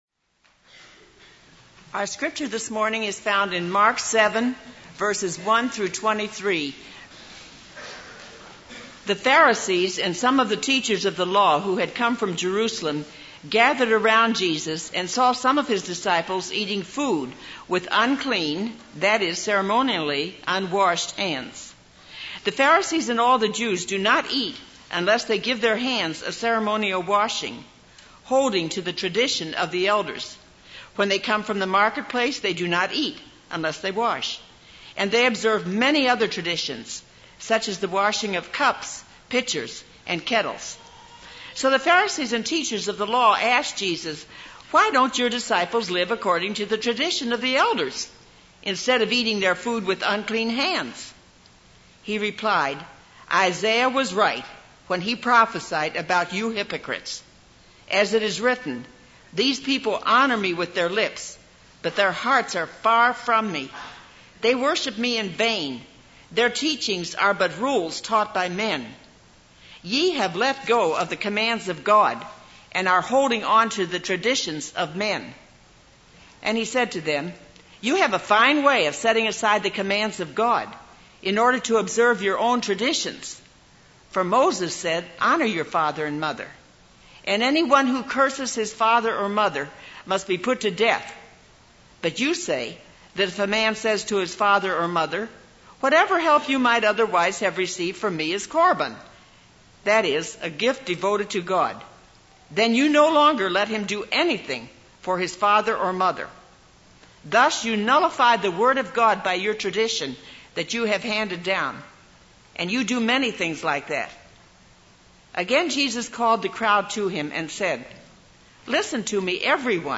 This is a sermon on Mark 7:1-23.